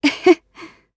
Glenwys-Vox-Laugh_jp.wav